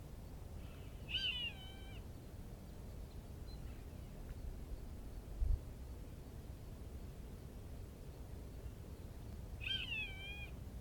Roadside Hawk (Rupornis magnirostris)
Life Stage: Adult
Location or protected area: Reserva Natural del Pilar
Condition: Wild
Certainty: Observed, Recorded vocal